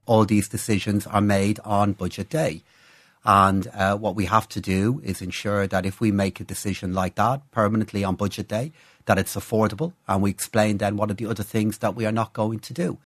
However, Finance Minister Paschal Donohoe says the coalition has been navigating economic uncertainty since then: